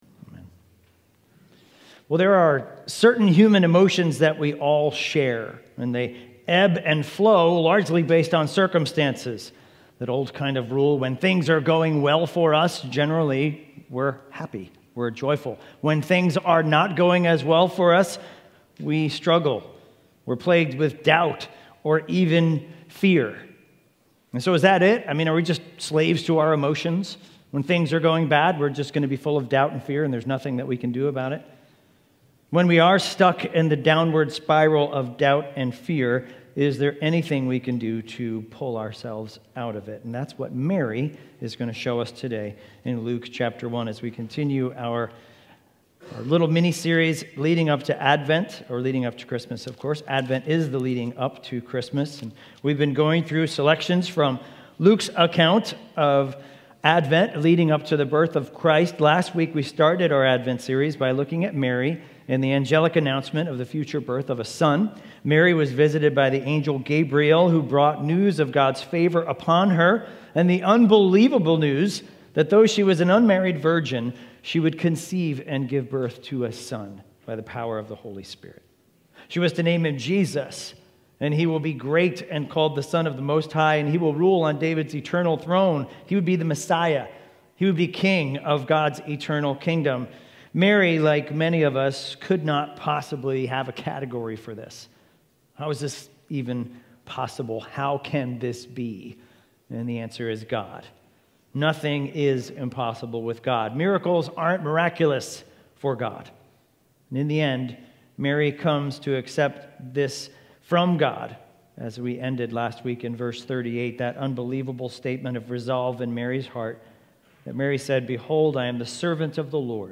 Sermons – Highlands Bible Church
Expositional preaching series from Luke to celebrate Advent 2024